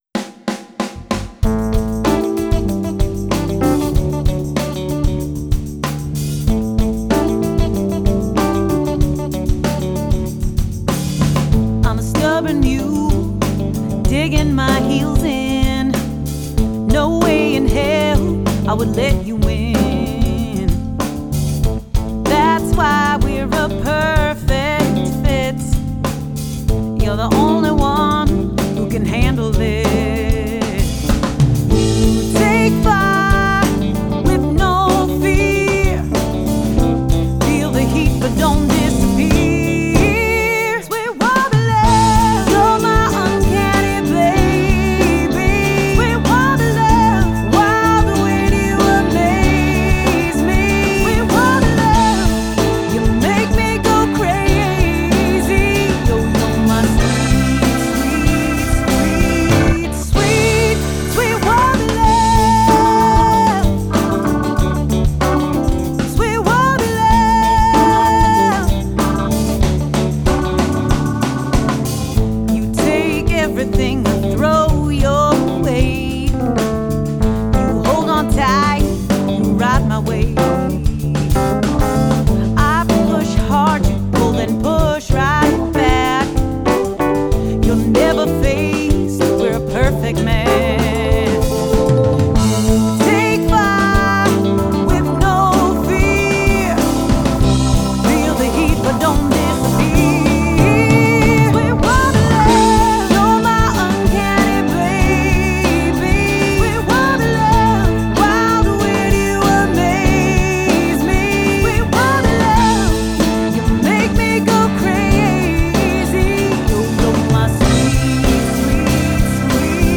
Full of soul
combines rock, pop, blues and funk in her first EP.